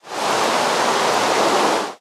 rain2.ogg